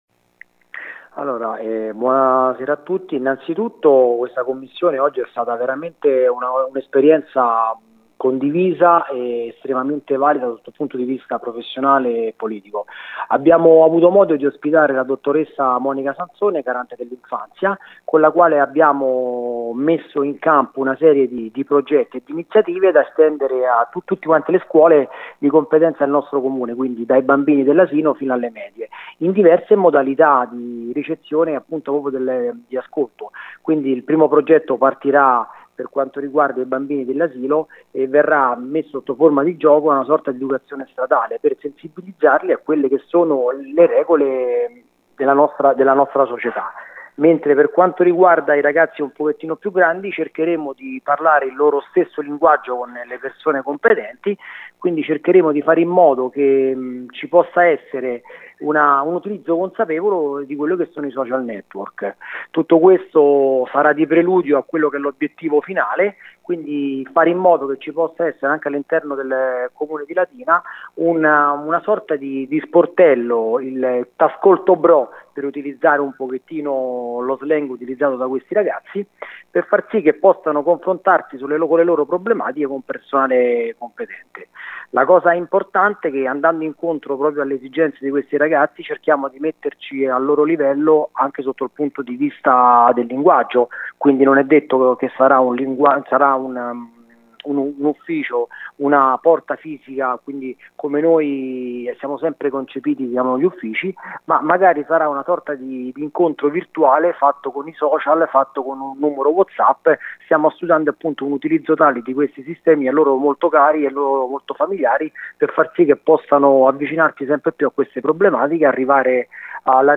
Quaranta i bambini di primaria coinvolti in questo primo modulo, come ha spiegato a Gr Latina il presidente Coriddi, che si dice determinato ad estendere il progetto anche ad altre scuole.